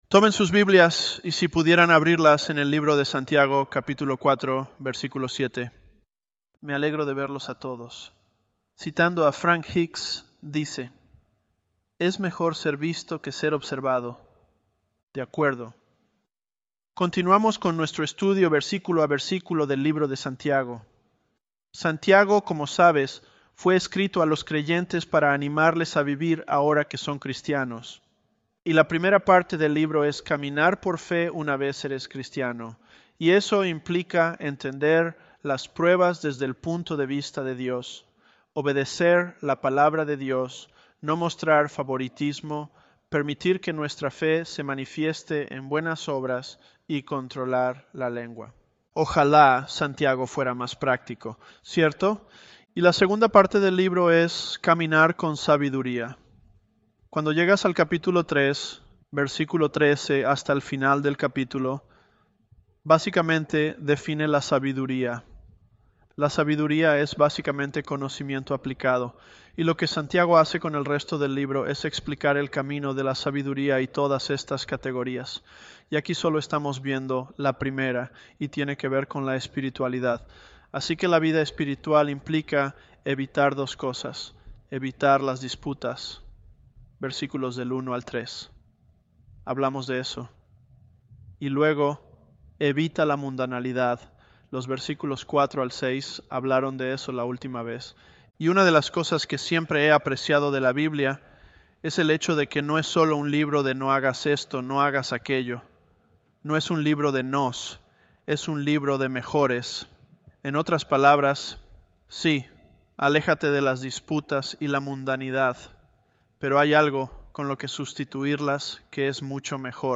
ElevenLabs_James022.mp3